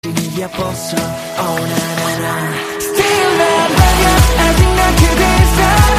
SK_guitar_fx_pick_slide